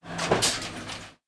machine_attack1c.wav